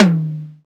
TOM XC.TOM07.wav